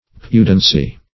Pudency \Pu"den*cy\, n. [L. pudens, p. pr. of pudere to be